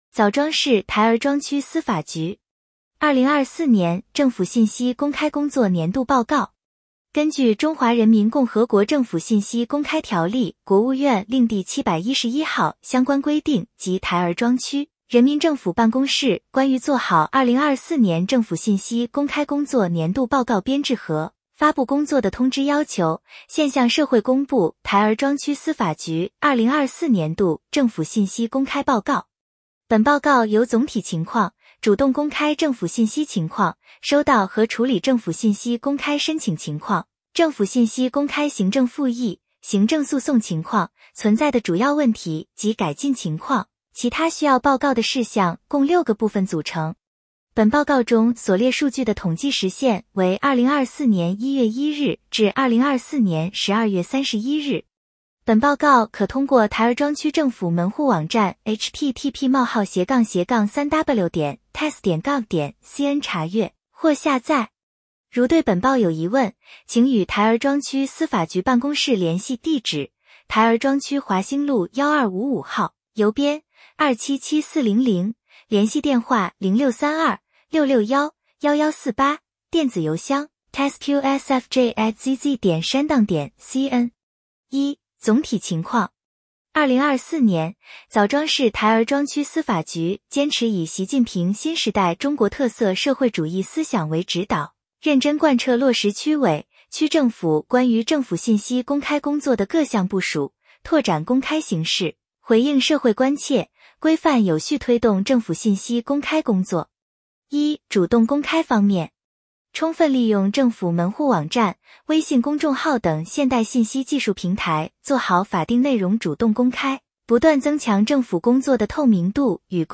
点击接收年报语音朗读 枣庄市台儿庄区司法局2024年政府信息公开工作年度报告 作者： 来自： 时间：2025-01-23 根据《中华人民共和国政府信息公开条例》（国务院令第 711号）相关规定及《台儿庄区人民政府办公室关于做好2024年政府信息公开工作年度报告编制和发布工作的通知》要求，现向社会公布台儿庄区司法局2024年度政府信息公开报告。